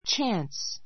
chance 中 A2 tʃǽns チャ ン ス 名詞 ❶ 機会, チャンス I had a chance to talk with him.